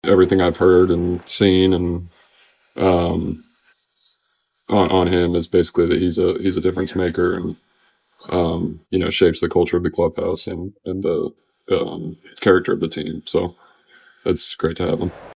Cy Young Award winner Paul Skenes says if you want to win, surround yourself with winners, and Burnett is a winner.